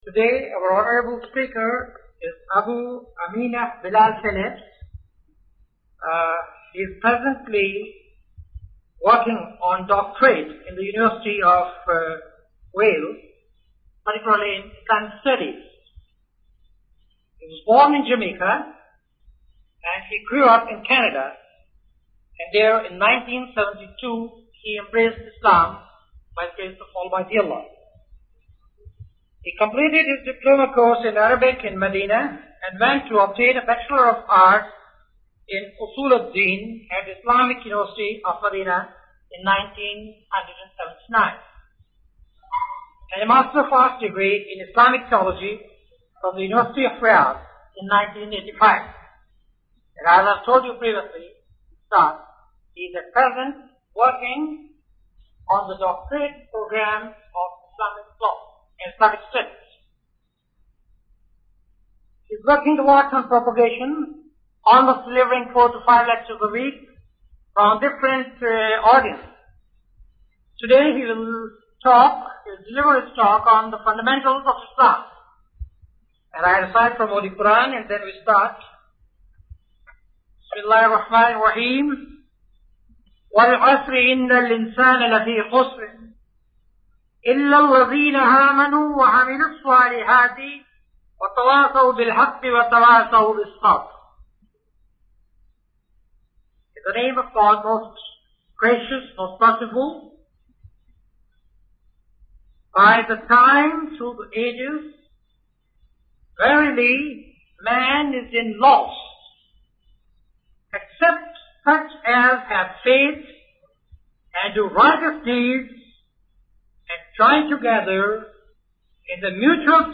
A lecture in English delivered by Sh. Bilal Philips in which he explains some important Islamic fund